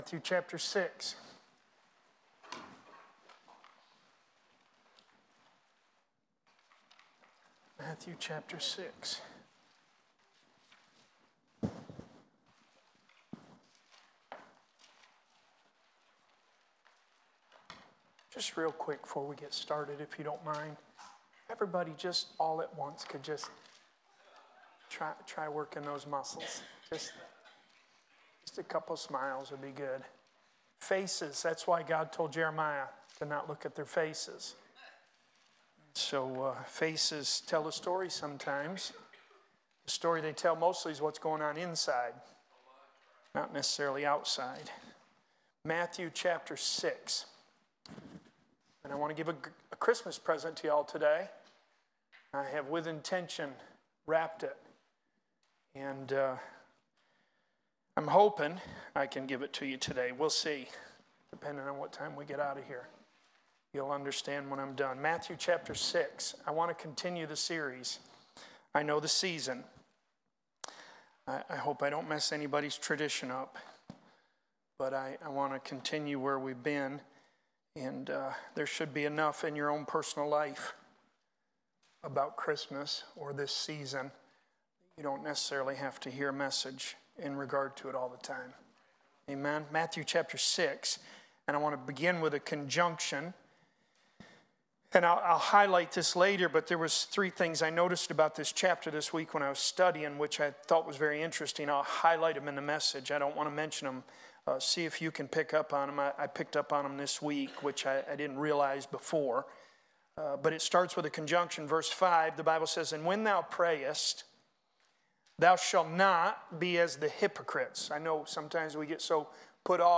Sermons | Graham Road Baptist Church